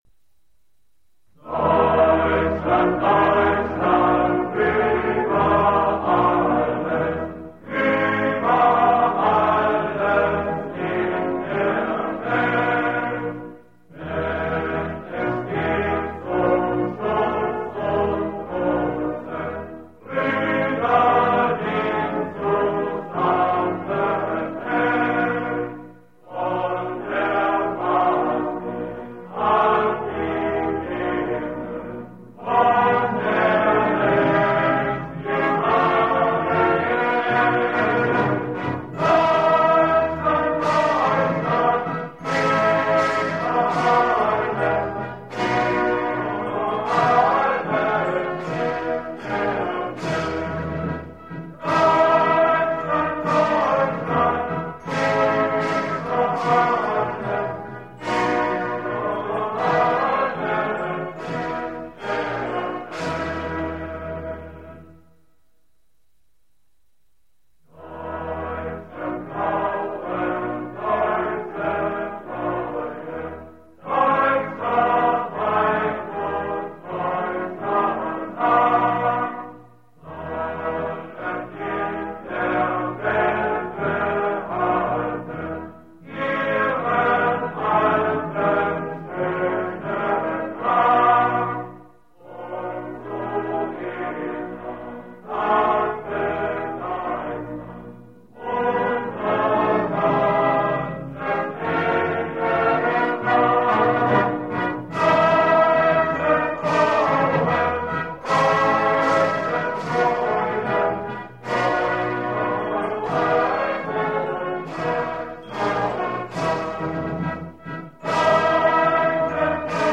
• Качество: высокое